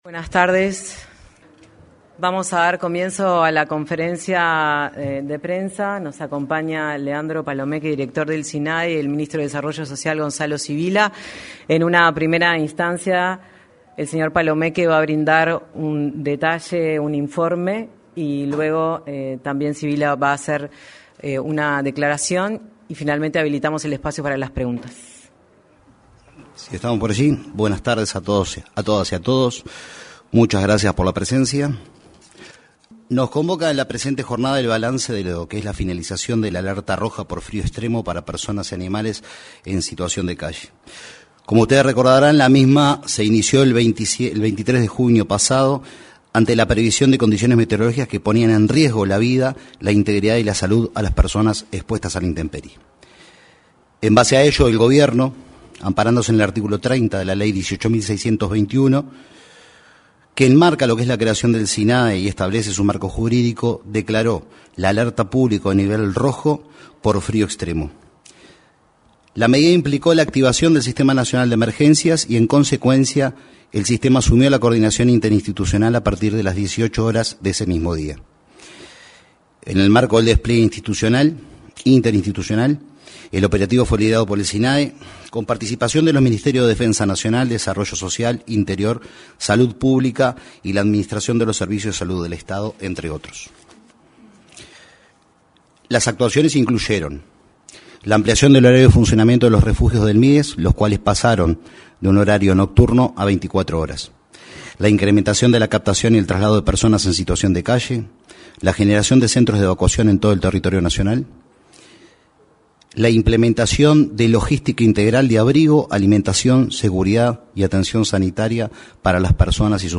Conferencia de prensa por cese y evaluación de alerta roja
Al finalizar la alerta roja por fríos extremos, se realizó una conferencia de prensa en la Torre Ejecutiva.
En la oportunidad, se expresaron el director del Sistema Nacional de Emergencias, Leandro Palomeque, y el ministro de Desarrollo Social, Gonzalo Civila.